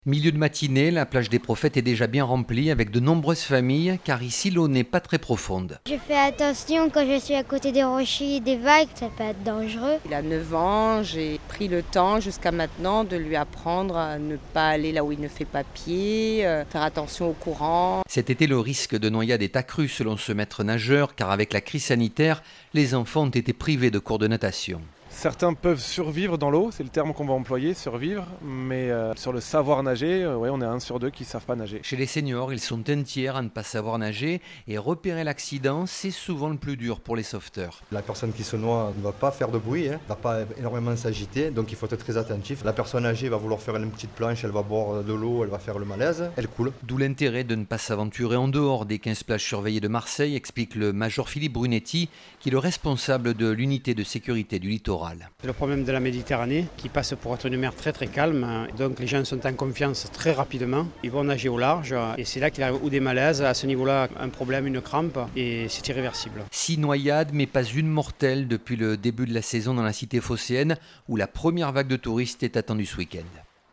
Les autorités appellent à la plus grande vigilance et conseillent aux baigneurs de privilégier les plages surveillées comme la plage des Prophètes, à Marseille, où, jeudi 1er juillet 2021 en milieu de matinée, de nombreuses familles étaient installées.